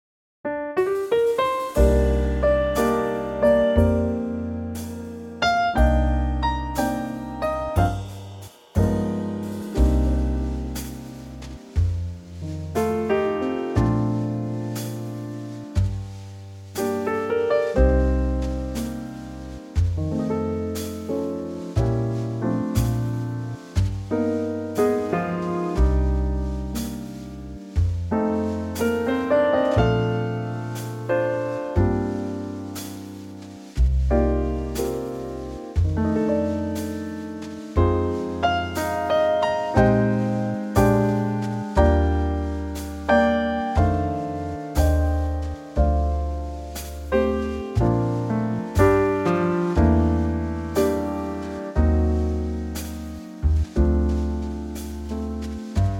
Unique Backing Tracks
key - Bb - vocal range - Bb to D